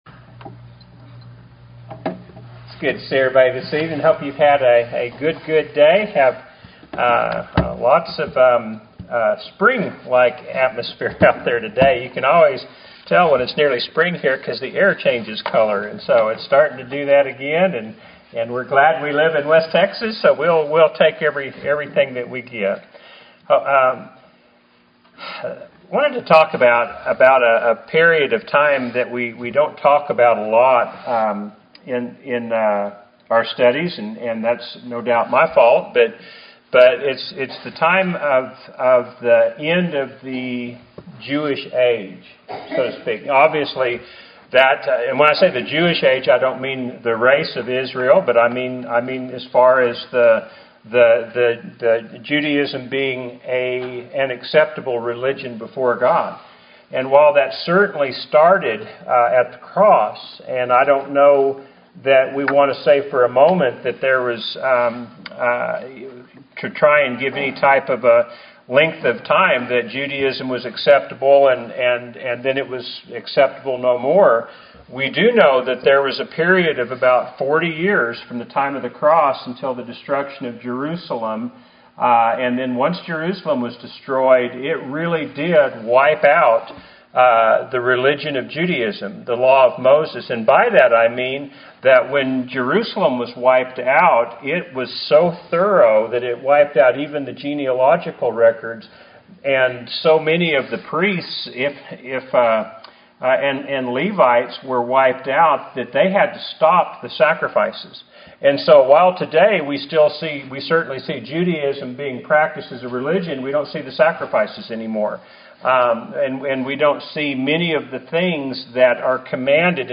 Happy Church of Christ Listen to Sermons